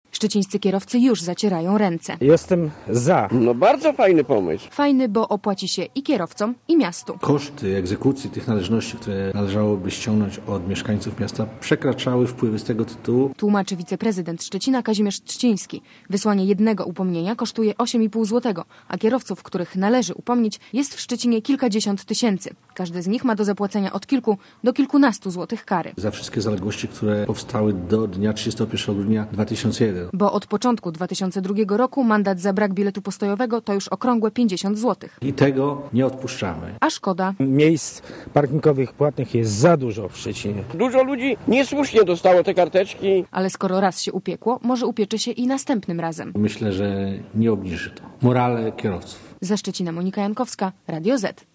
Posłuchaj relacji korespondenta Radia Zet (0,4 MB)